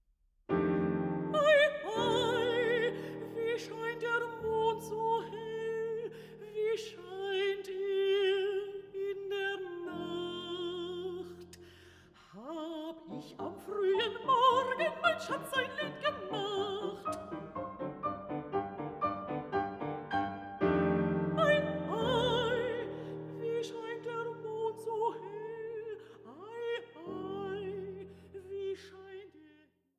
Mezzosopran
Klavier